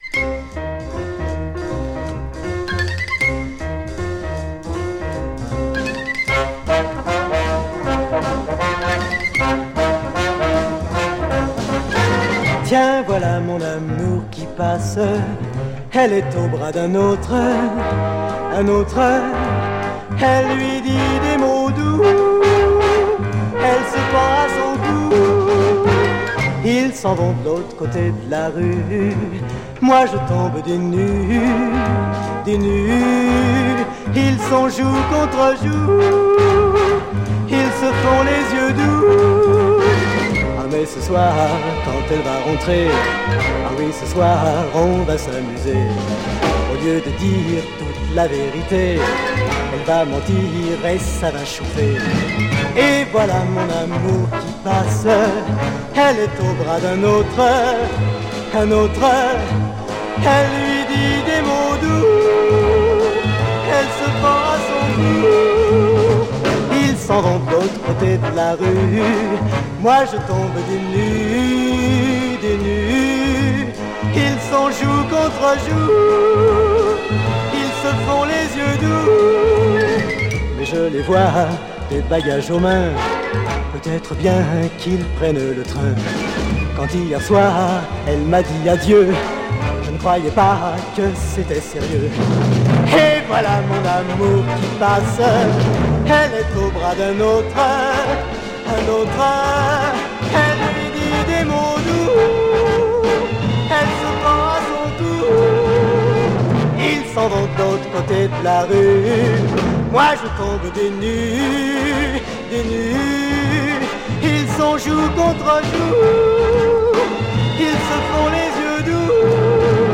French popcorn jazz chanson EP